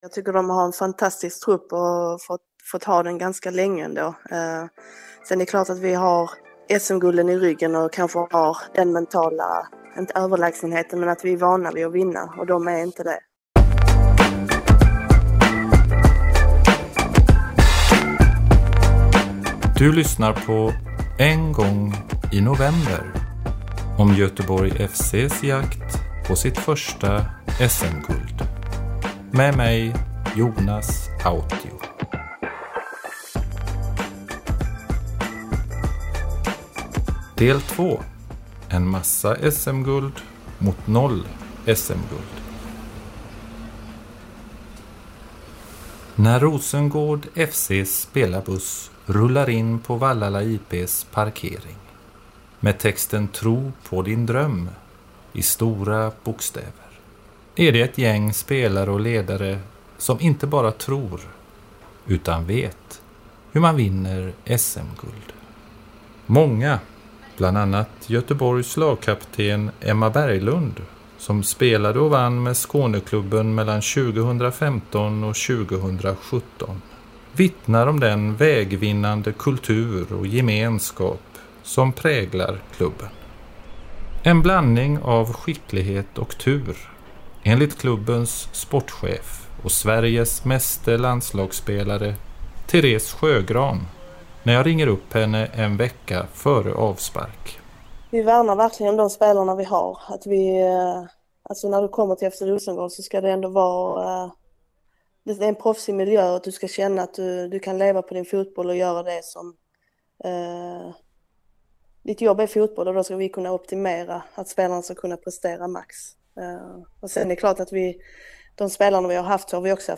Intervjuer med Therese Sjögran